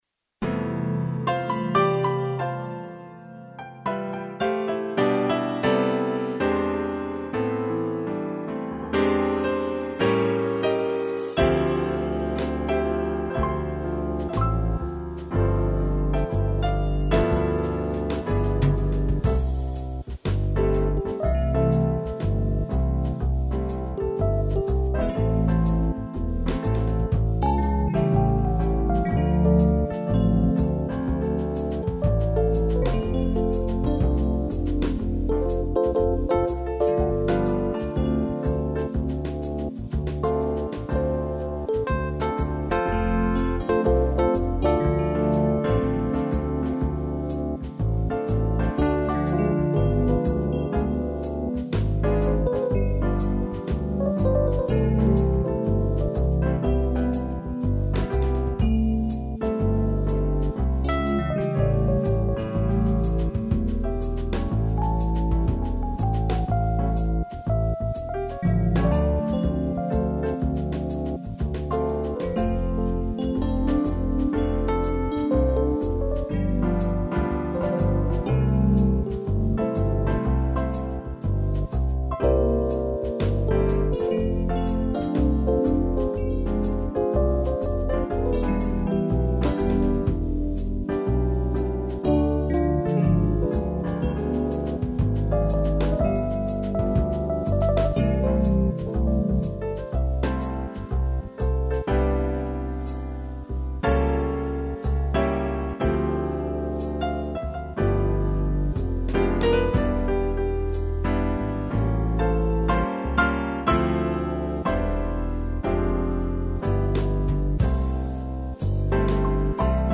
teclado